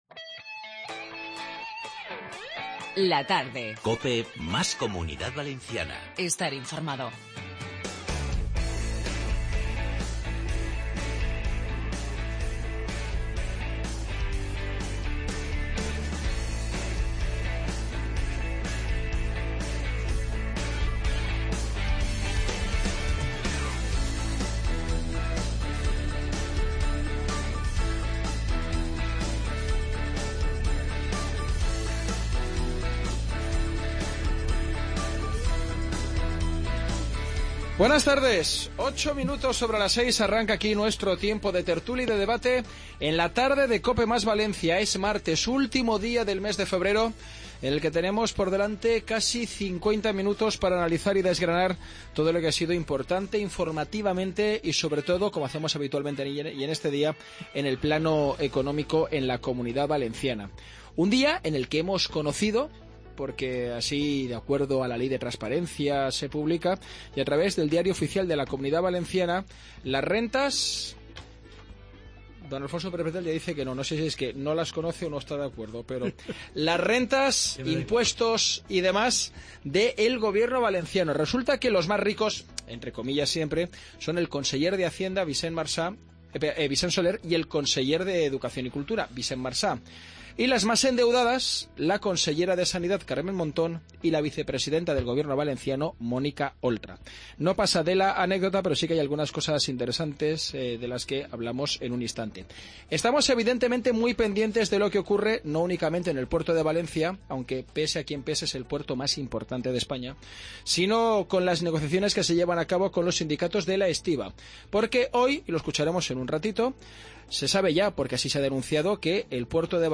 28.02.2017 LTCOPE Tertulia